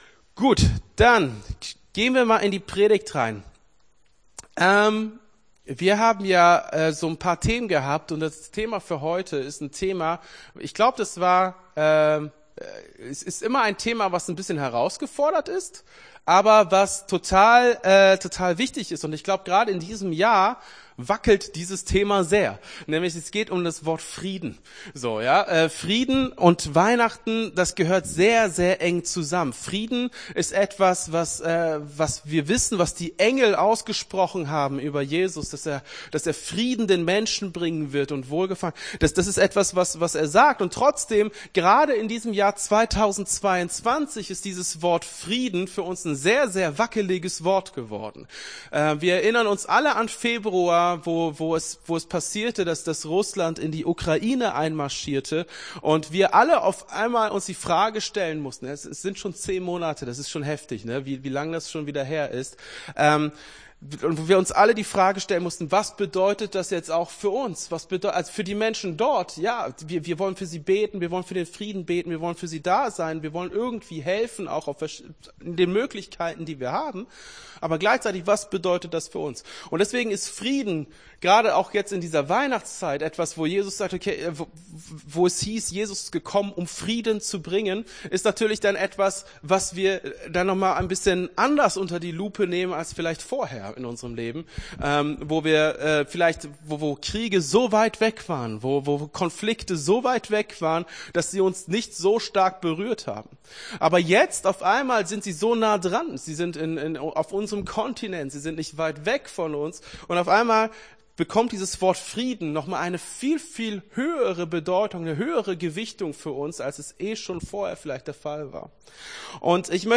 Gottesdienst 18.12.22 - FCG Hagen